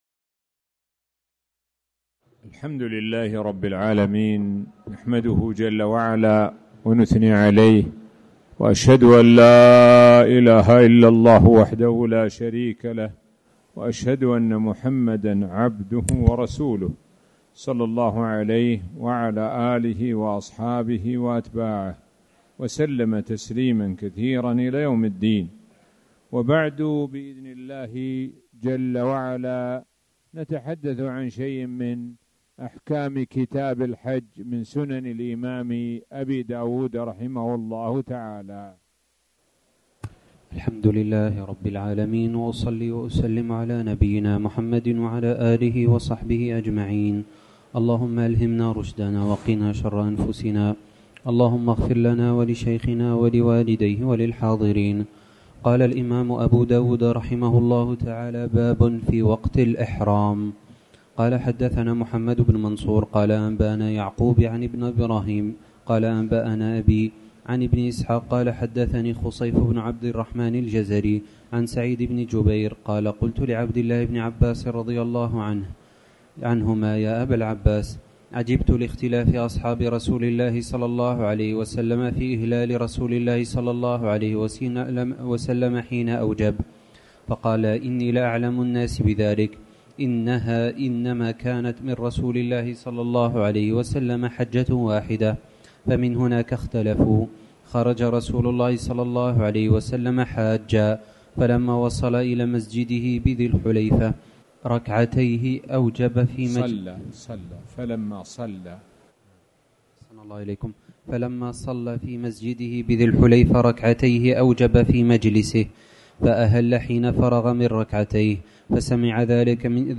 تاريخ النشر ٢٤ ذو القعدة ١٤٣٨ هـ المكان: المسجد الحرام الشيخ: معالي الشيخ د. سعد بن ناصر الشثري معالي الشيخ د. سعد بن ناصر الشثري كتاب الحج